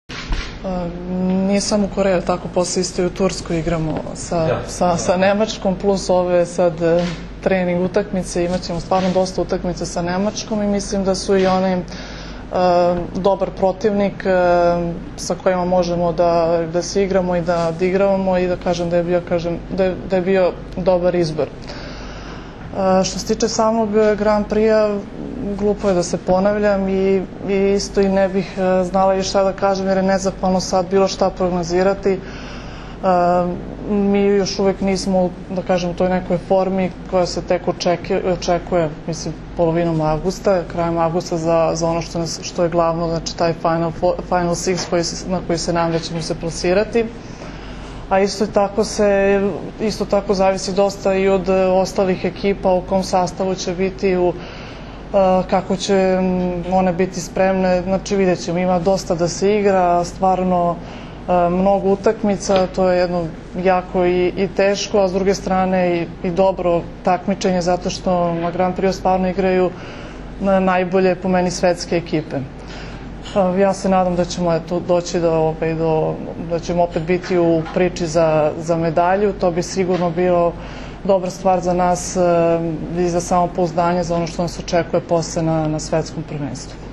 Tim povodom, danas je u beogradskom hotelu “M” održana konferencija za novinare, kojoj su prisustvovali Zoran Terzić, Maja Ognjenović, Jelena Nikolić i Milena Rašić.
IZJAVA JELENE NIKOLIĆ